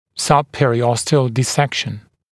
[ˌsʌbˌperɪ’ɔstɪəl dɪ’sekʃn][ˌсабˌпэри’остиэл ди’сэкшн]субпериостальный разрез